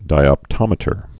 (dīŏp-tŏmĭ-tər)